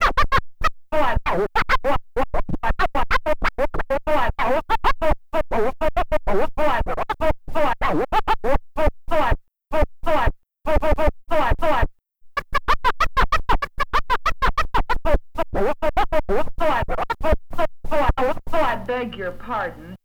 Track 14 - Turntable Scratches 02.wav